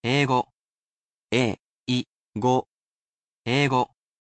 I am sure to repeat the main word(s) slowly, but I read the sentences at a natural pace, so do not worry about repeating after the sentences.